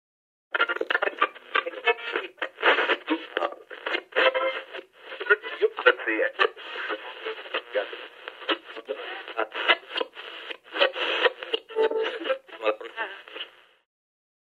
Звуки радиосигналов
Звук переключения радиочастот